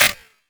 edm-perc-11.wav